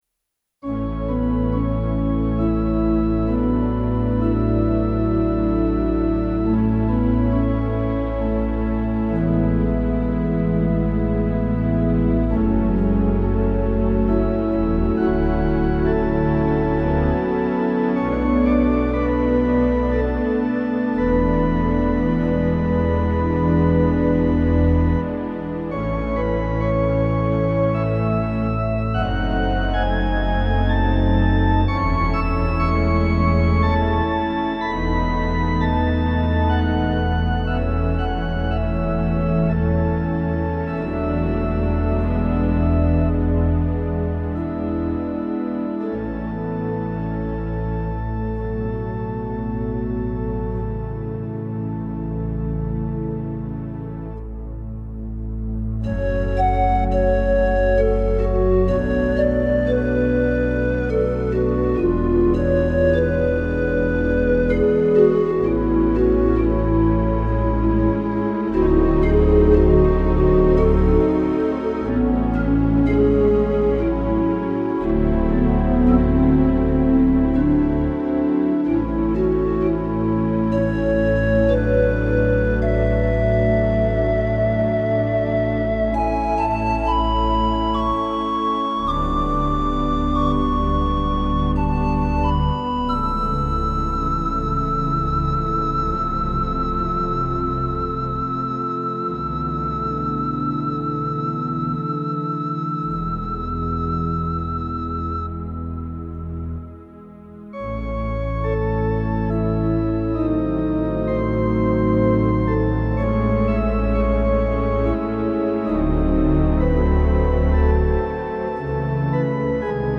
Rodgers 205 Hybrid Organ (circa 1978)